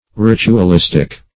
Ritualistic \Rit`u*al*is"tic\, a.